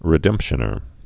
(rĭ-dĕmpshə-nər)